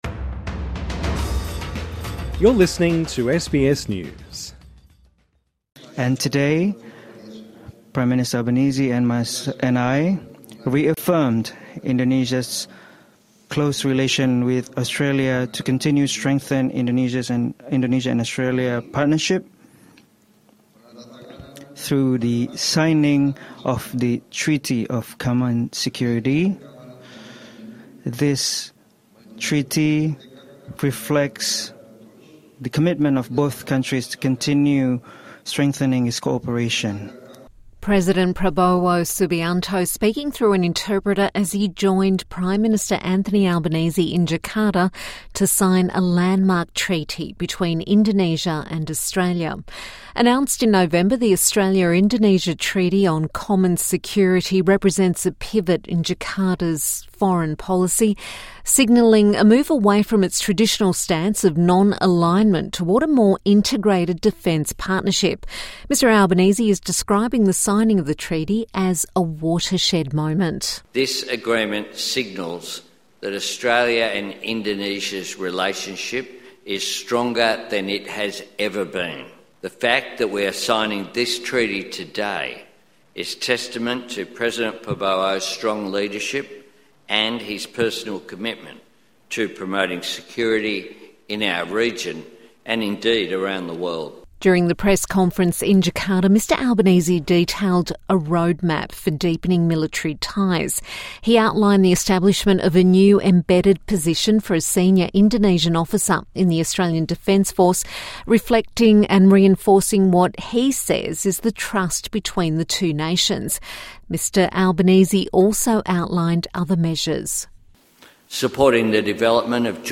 President Prabowo Subianto speaking through an interpreter - as he joined Prime Minister Anthony Albanese in Jakarta to sign a landmark treaty between Indonesia and Australia.